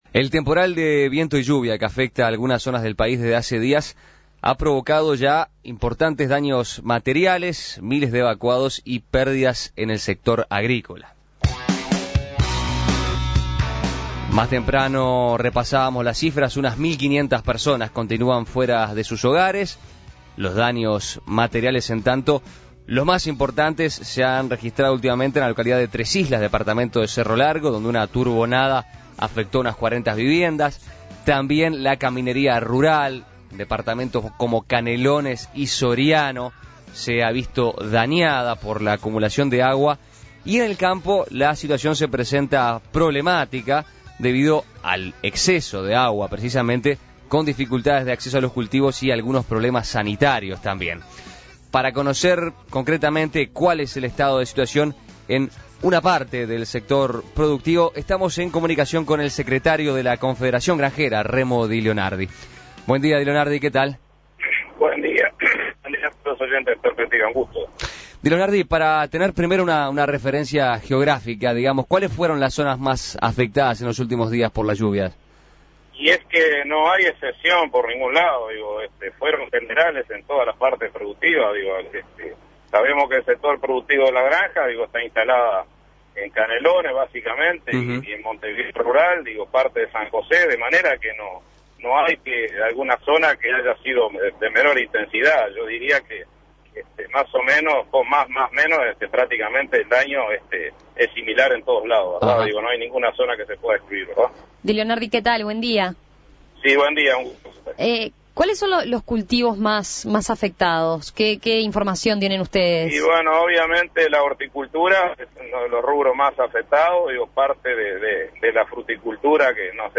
(emitido a las 7.47 hs.)